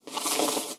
纸卷轴8.mp3